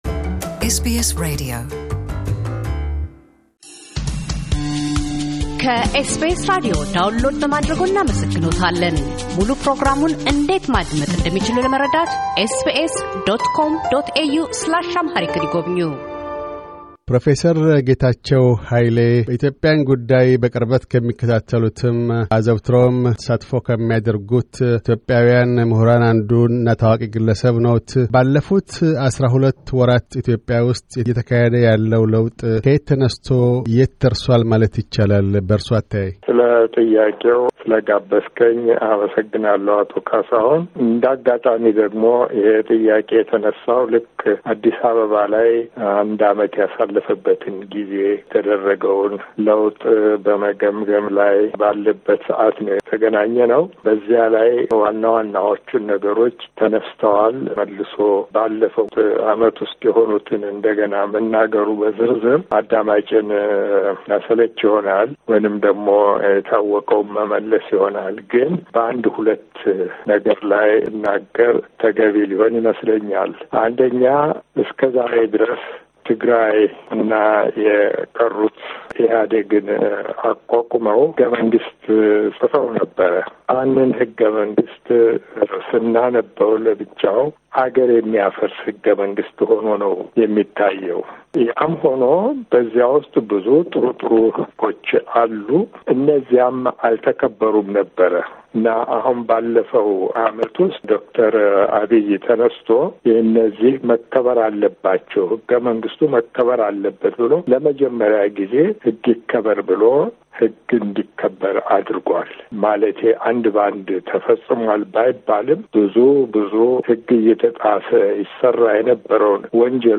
በሚል የመነጋገሪያ አጀንዳ በ2019 ካደረግናቸው ቃለ ምልልሶች ቀንጭበን አቅርበናል። ኢትዮጵያ ውስጥ የለውጡ ሂደት በመጀመሪያዎቹ 12 ወራት ውስጥ ስላበረከታቸው ማለፊያ አስተዋጽዖዎች፣ ያሳደራቸው ስጋቶችና ተስፋዎች ላይ ግለ አተያያቸውን አጋርተውን ነበር።